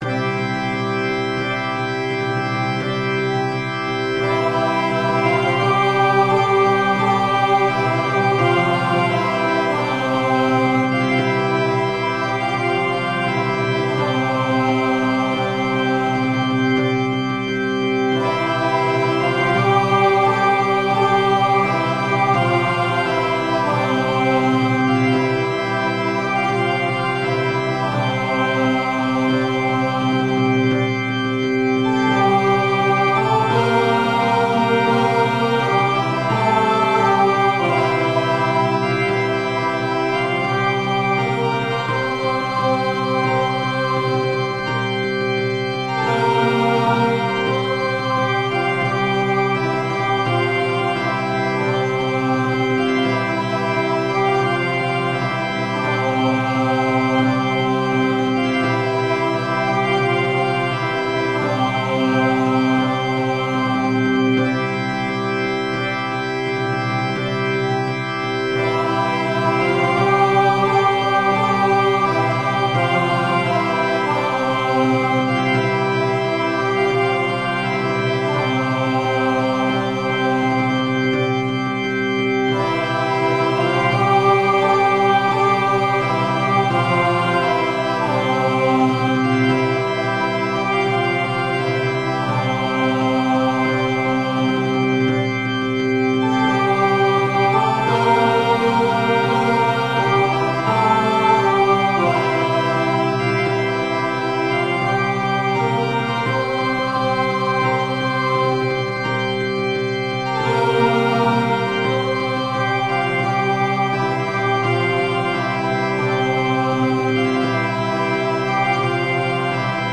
I Know That My Redeemer Lives (by Joseph Knapicius -- Choir Unison, Organ/Organ Accompaniment, Percussion)
Voicing/Instrumentation: Choir Unison , Organ/Organ Accompaniment , Percussion We also have other 69 arrangements of " I Know That My Redeemer Lives ".